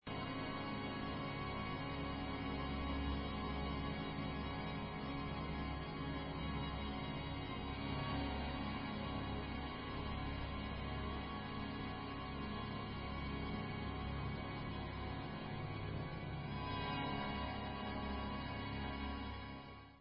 For Baritone and Orchestra
sledovat novinky v kategorii Vážná hudba